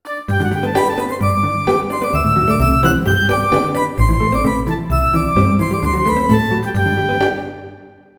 Tonalidad de Sol mayor. Ejemplo.
desenfadado
festivo
jovial
melodía
sintetizador